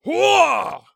ZS重击3.wav
ZS重击3.wav 0:00.00 0:00.97 ZS重击3.wav WAV · 83 KB · 單聲道 (1ch) 下载文件 本站所有音效均采用 CC0 授权 ，可免费用于商业与个人项目，无需署名。
人声采集素材/男3战士型/ZS重击3.wav